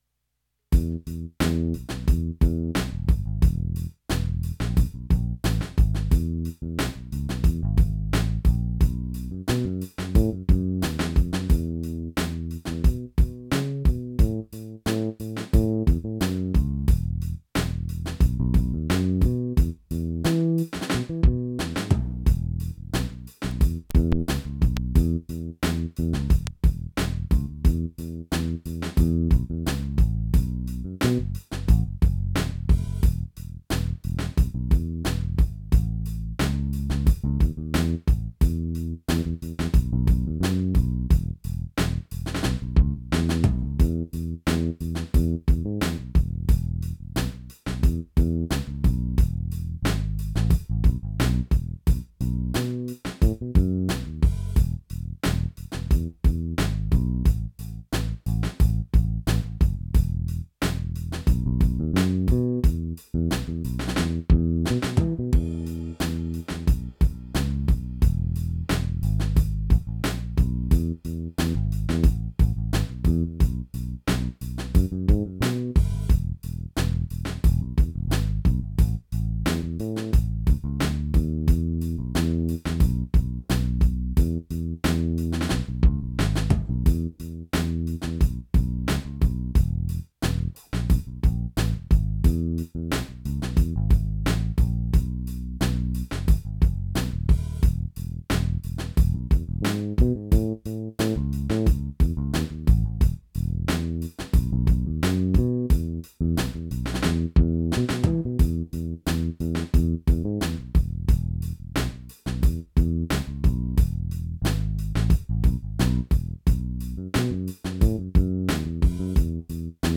Funky Reggae Lesson